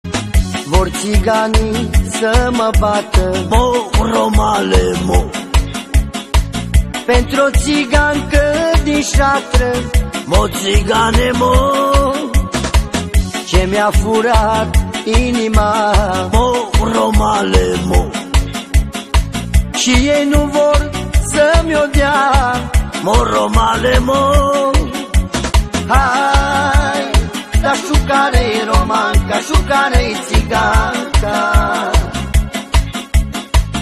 • Качество: 96, Stereo
поп
веселые
цыганские
румынские
свадебные